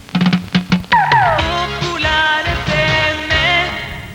Indicatiu de la cadena.